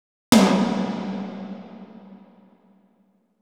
Techno / Drum / TOM003_TEKNO_140_X_SC2.wav